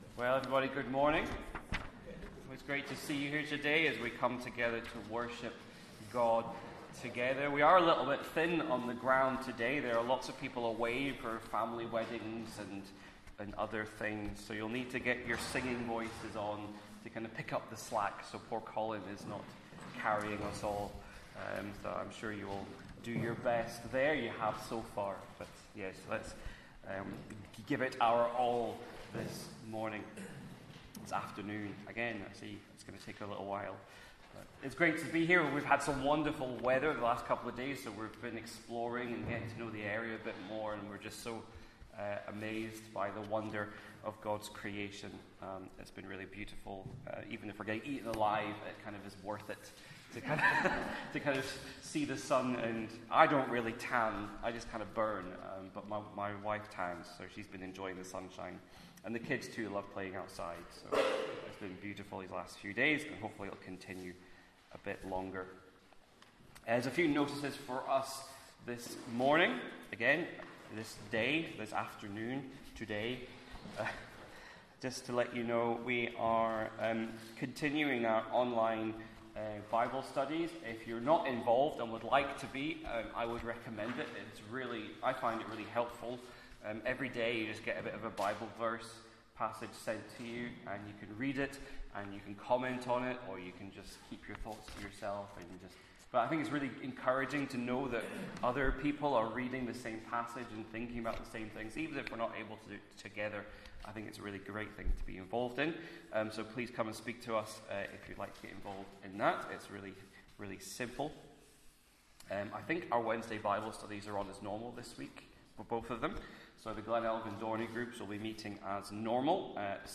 Sunday Service 22nd Sept 2024